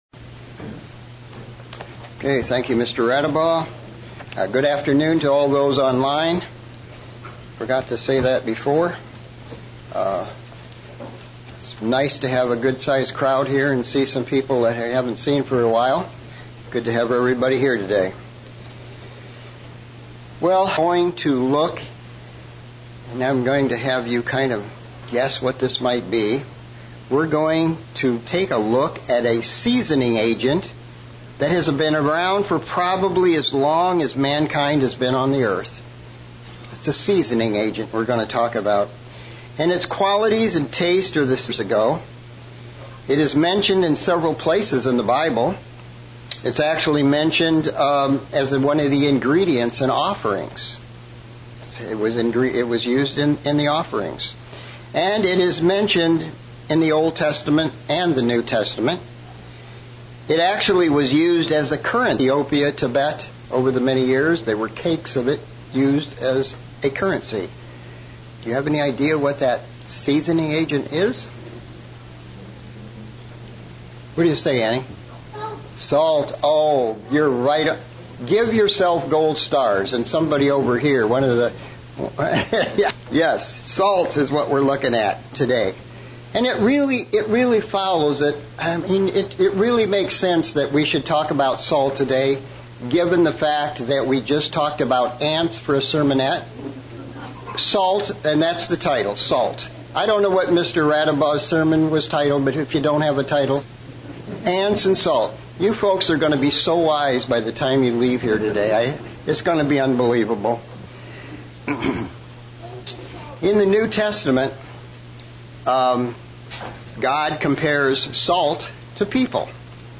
Sermon looking at salt and how it is used both in the sacrificial system, as well as in the culture of the time. This message also looks at the use of the covenant of salt God made with the priesthood and why this is important